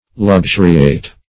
Luxuriate \Lux*u"ri*ate\, v. i. [imp.